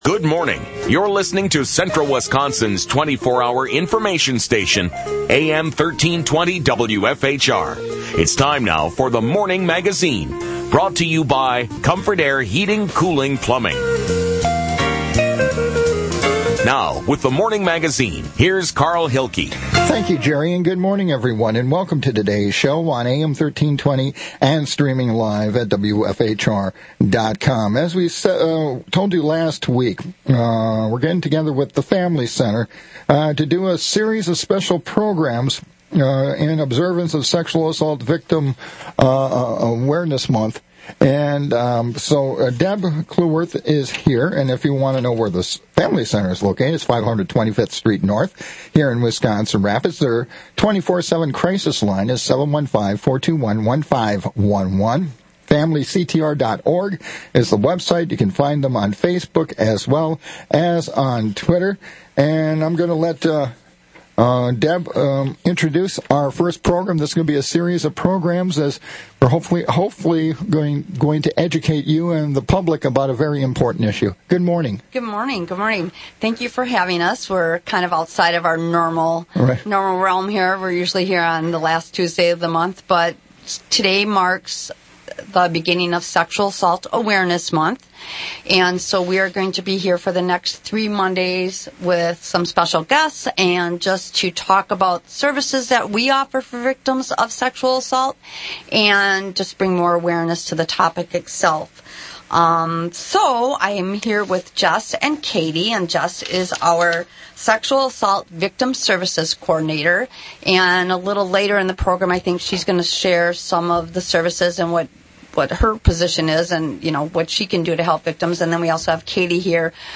WFHR 1320 AM radio for special presentations on April 7, April 21, and April 28 for special segments featuring advocates from The Family Center. Topics will include Sexual Assault Awareness Month, sexual assault within the Hmong culture, and how to respond to someone who discloses abuse in their relationship.